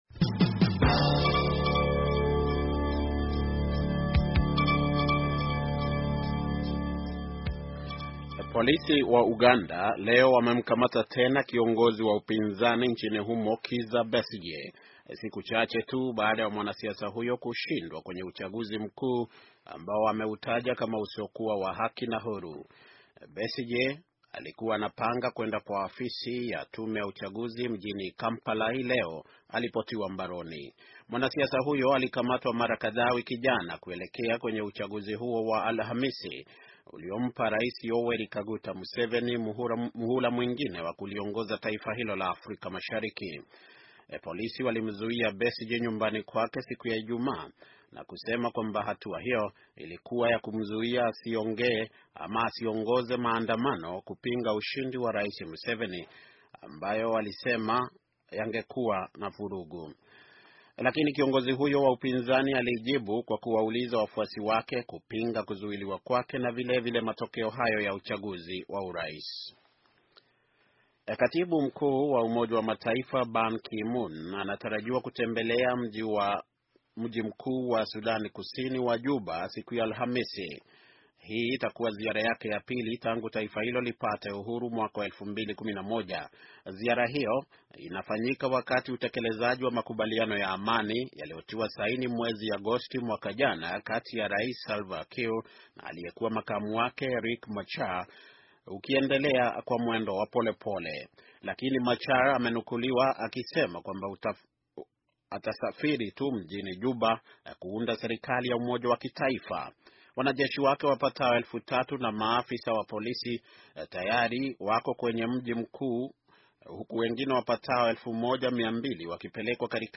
Taarifa ya habari - 6:29